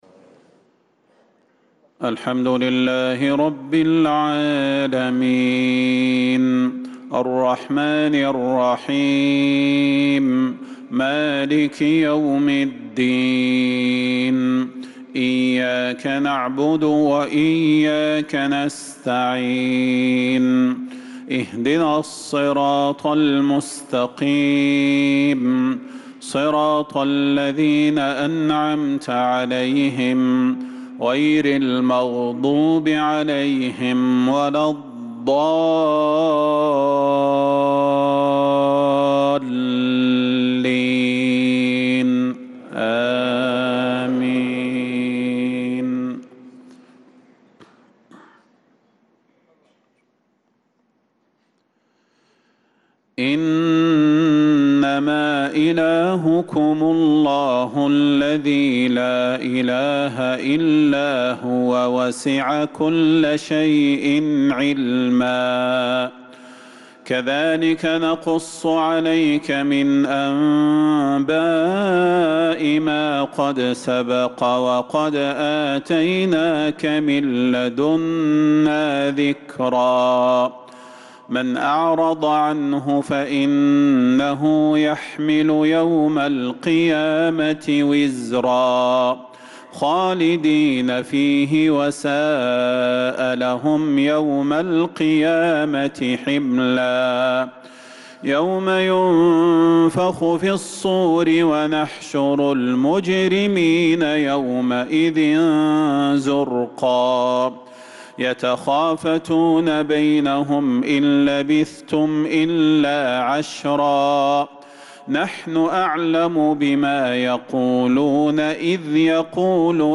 صلاة العشاء للقارئ صلاح البدير 16 شوال 1445 هـ
تِلَاوَات الْحَرَمَيْن .